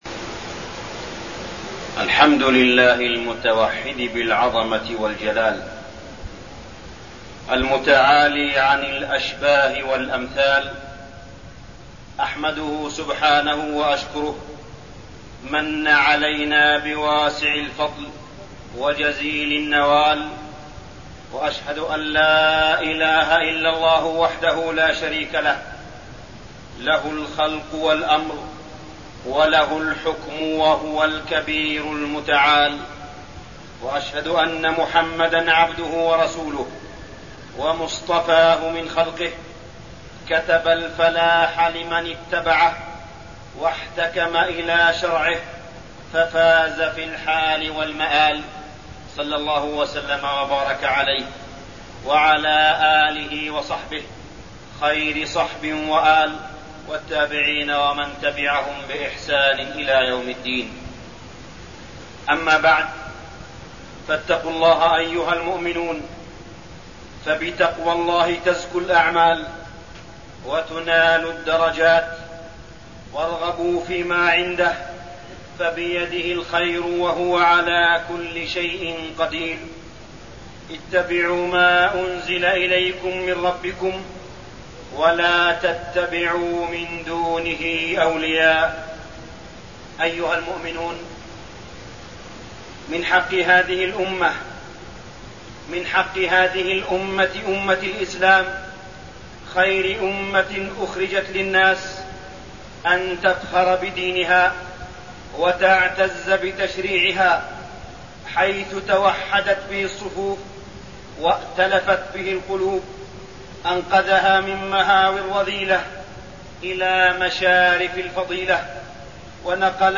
تاريخ النشر ٩ ربيع الثاني ١٤٠٩ هـ المكان: المسجد الحرام الشيخ: معالي الشيخ أ.د. صالح بن عبدالله بن حميد معالي الشيخ أ.د. صالح بن عبدالله بن حميد كتاب الله العظيم